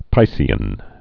(pīsē-ən)